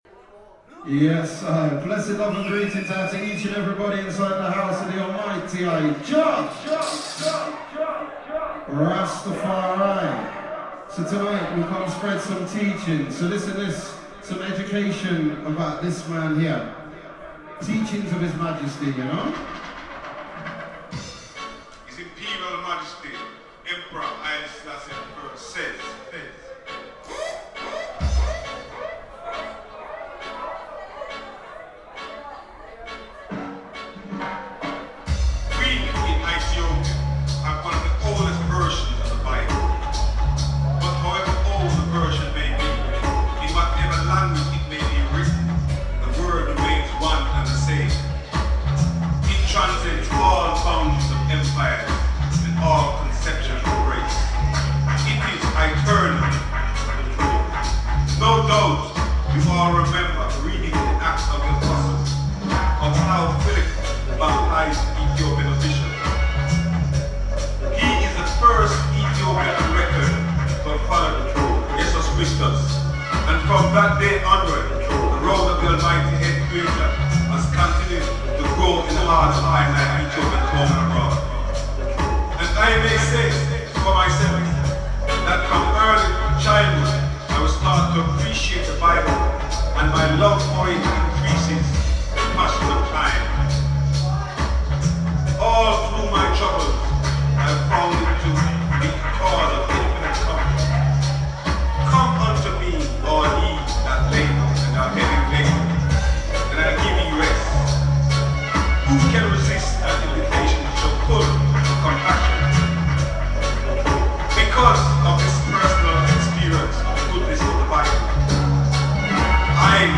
A Celebration of Sound System Culture